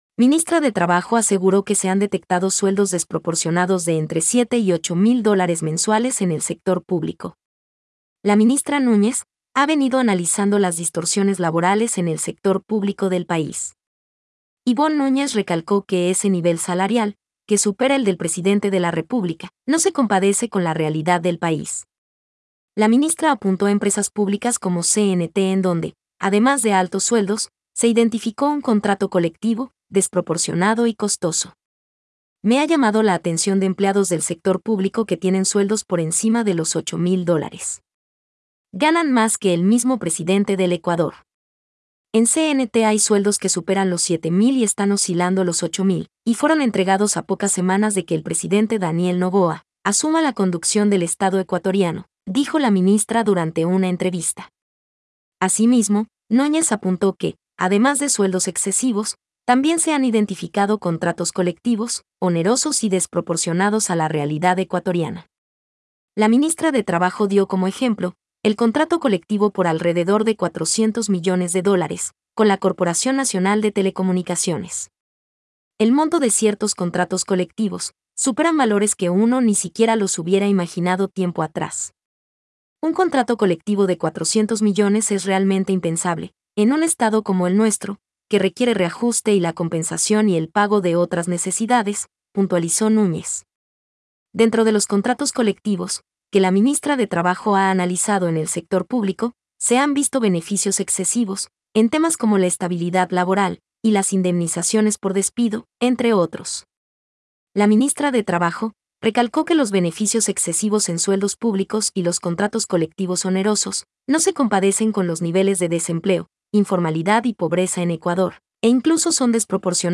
Noticia hablada